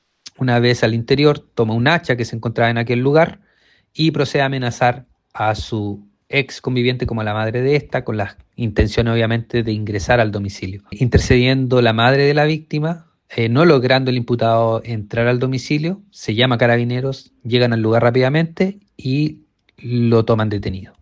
El hecho se registró en la población San Pedro de dicha comuna, donde llegó el imputado para ingresar al domicilio de su ex pareja, tomando el hacha para intentar atacar a ambas víctimas, según lo narrado por el fiscal subrogante Alejandro López.